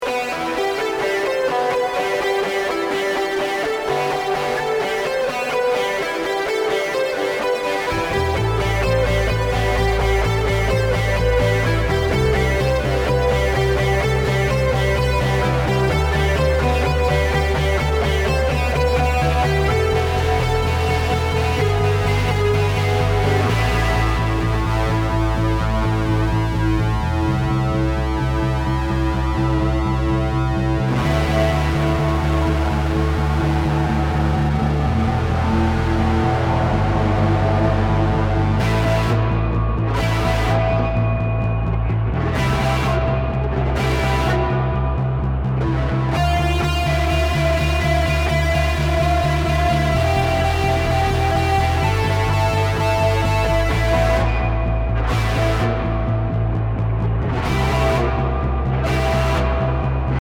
Detroit Punk Rock / Hardcore